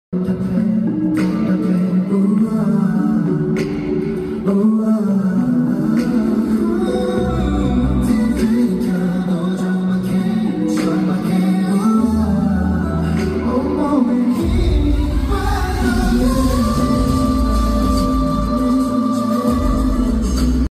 World Tour in Macau